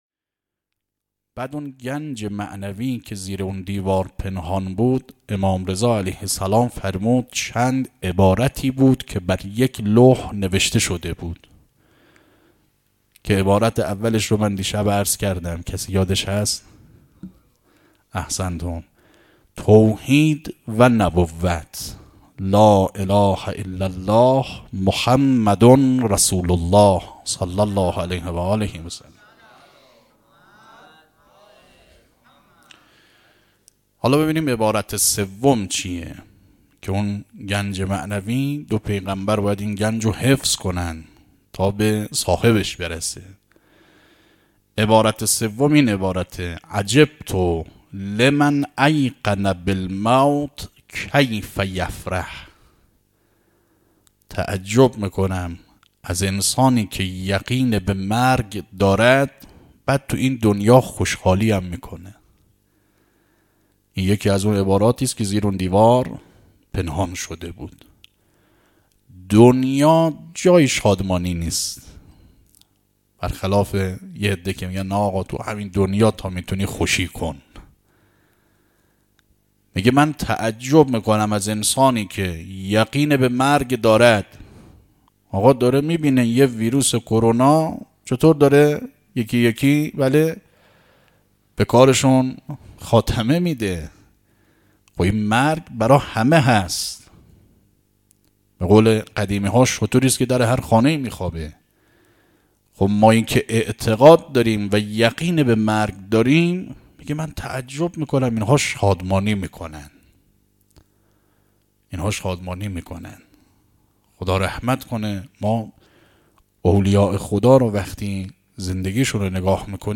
گلزار شهدای هرمزآباد
دهه اول محرم الحرام 1399